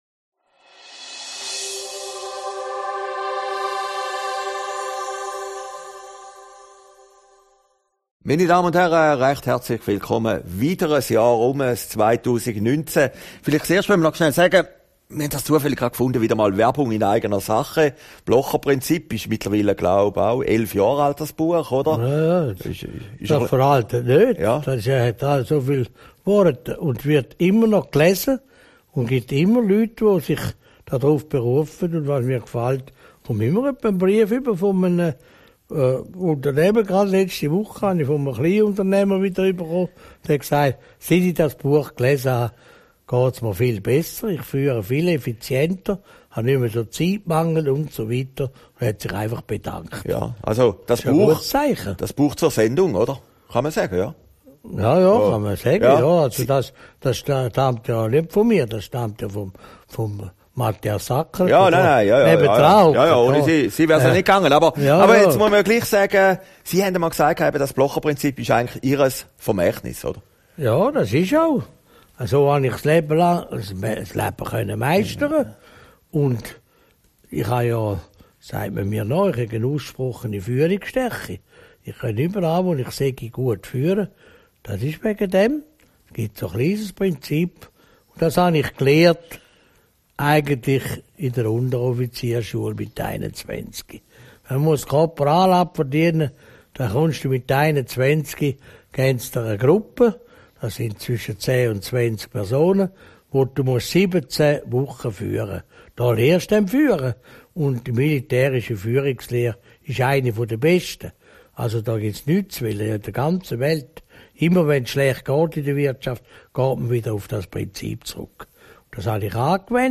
Aufgezeichnet in Herrliberg, 28.